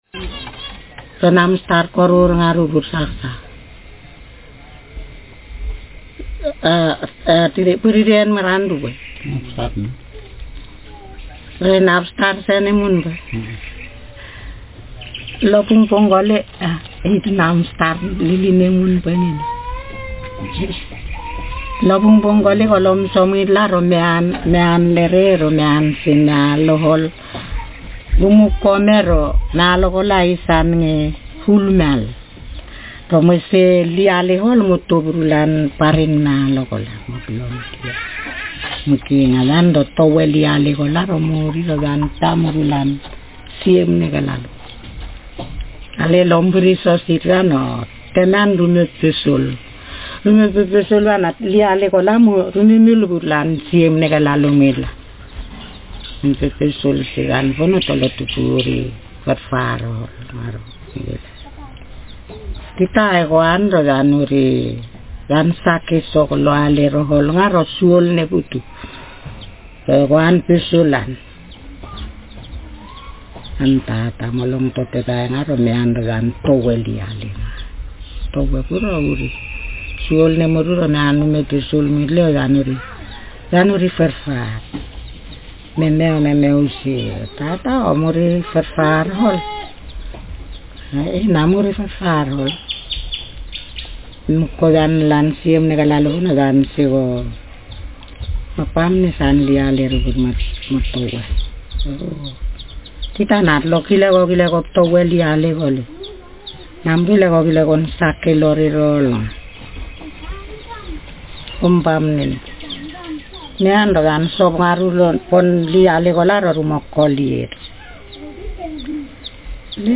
Speaker sexf
Text genretraditional narrative